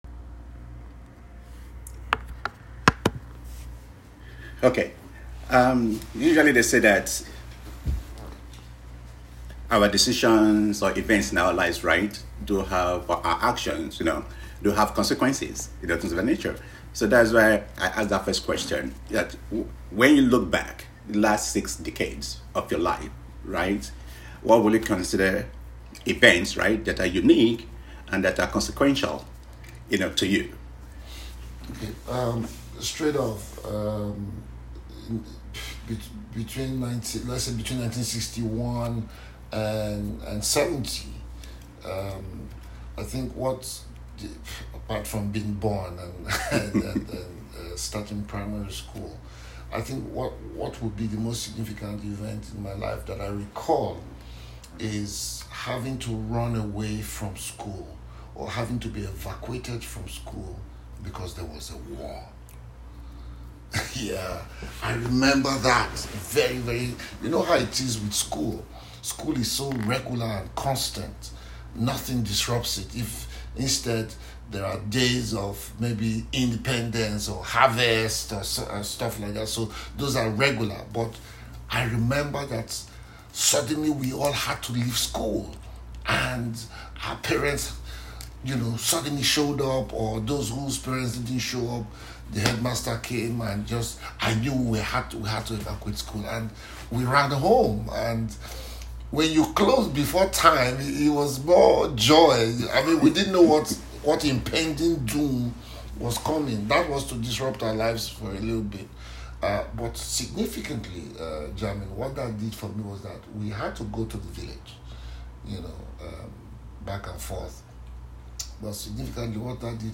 Richard Mofe-Damijo: The Miami Interview
Conversation-with-Richard-Mofe-Damijo.m4a